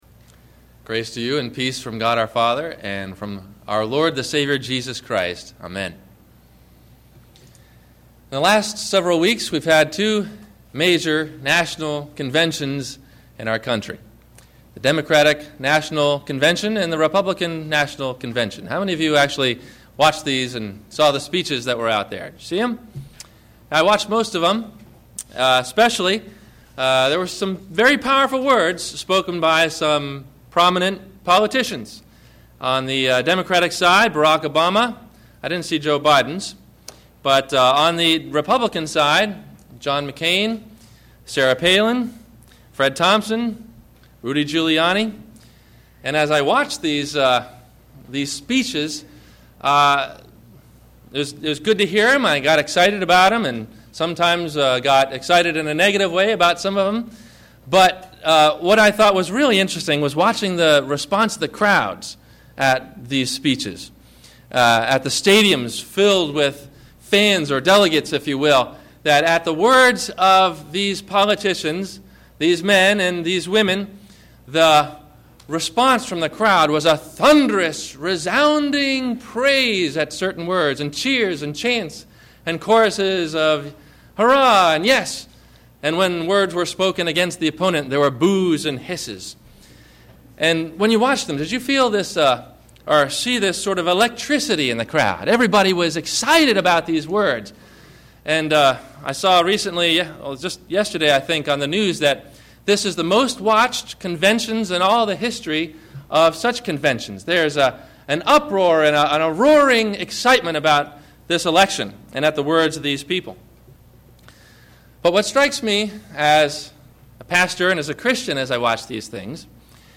No Other Gospel – Faith Alone - Sermon - September 07 2008 - Christ Lutheran Cape Canaveral